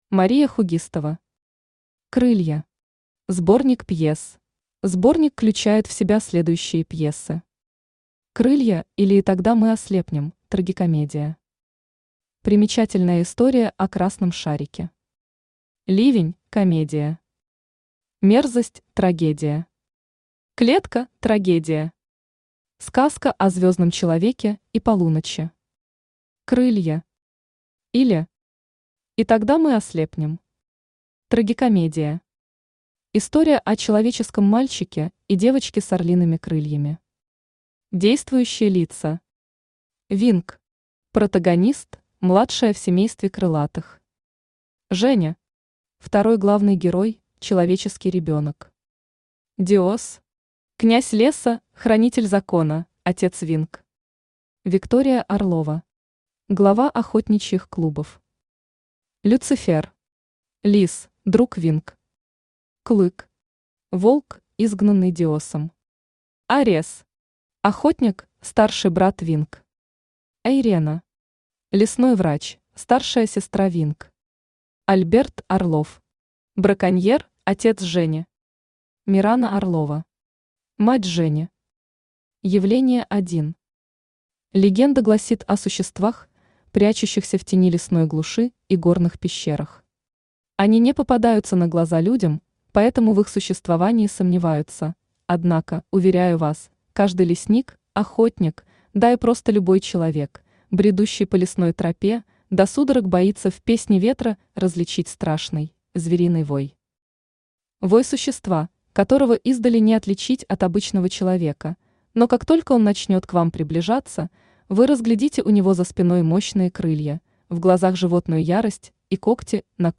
Аудиокнига Крылья. Сборник пьес | Библиотека аудиокниг
Сборник пьес Автор Мария Хугистова Читает аудиокнигу Авточтец ЛитРес.